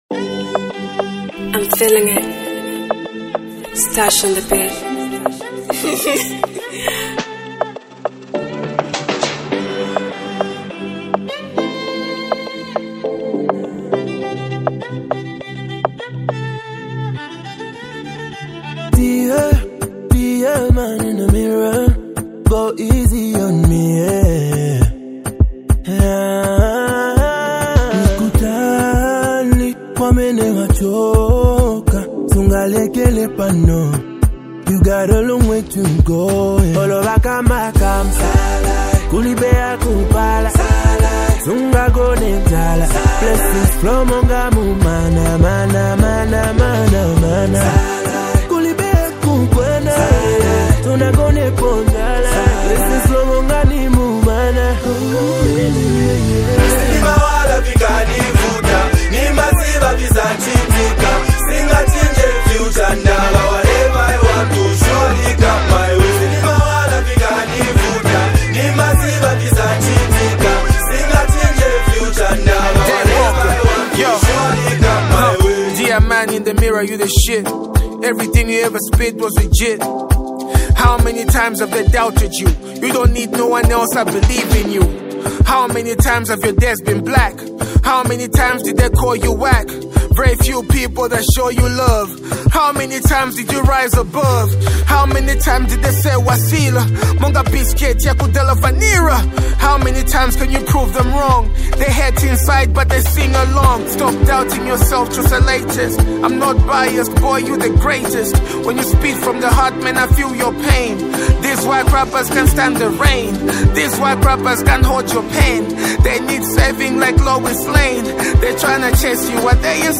compelling vocals